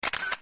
shutter.mp3